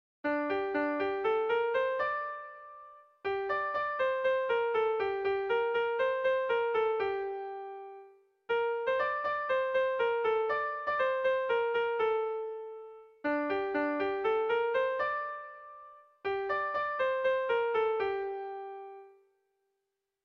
Bertso melodies - View details   To know more about this section
Irrizkoa
ABBDEAB